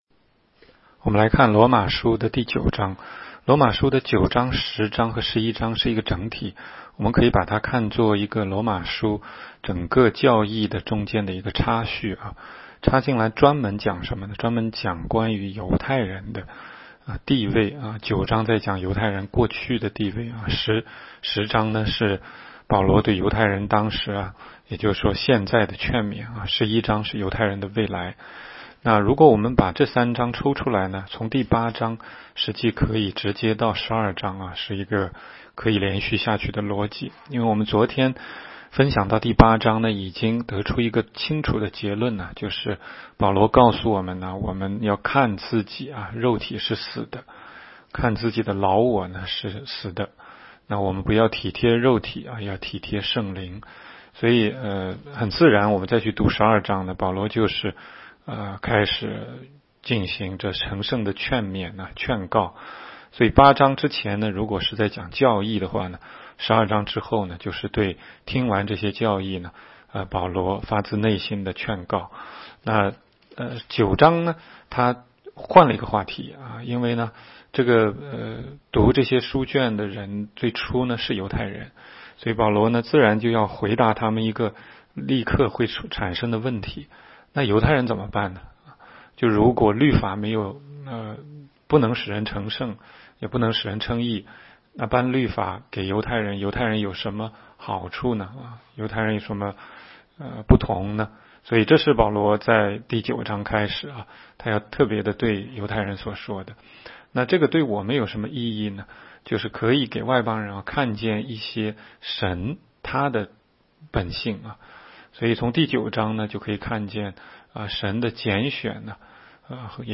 16街讲道录音 - 每日读经-《罗马书》9章